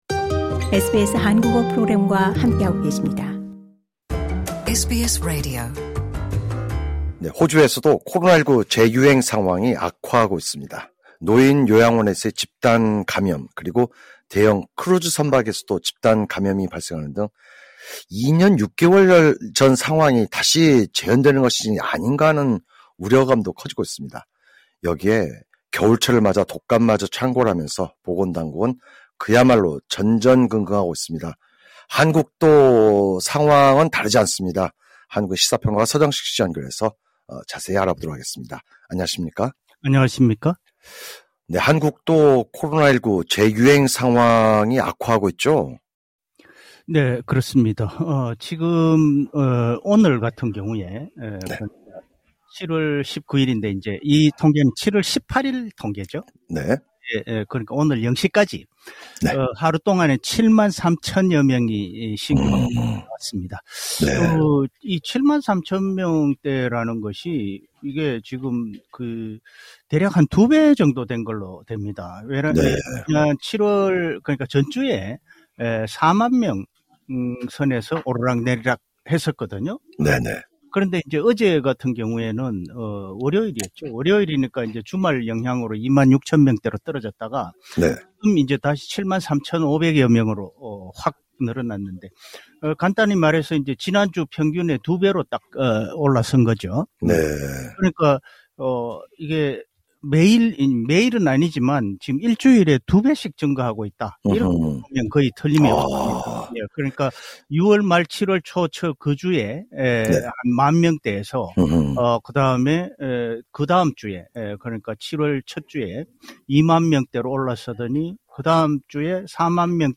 분석: 시사 평론가